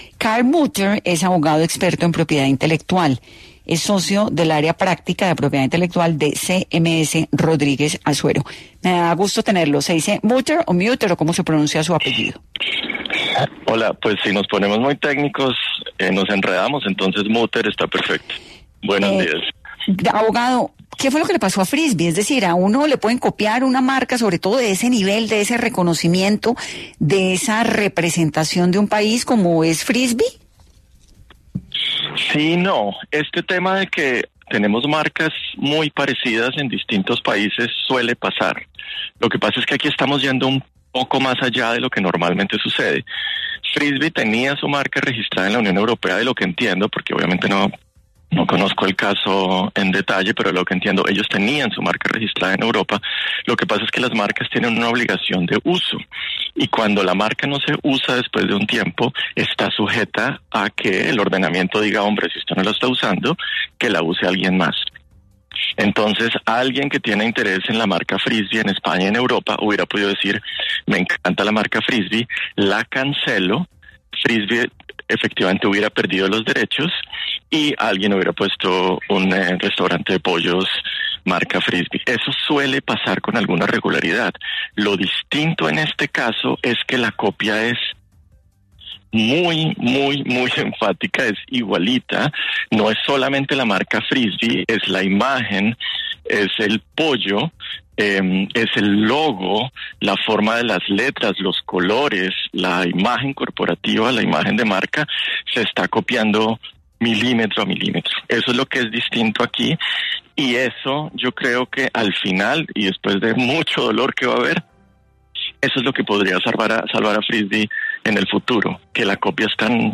abogado experto en propiedad intelectual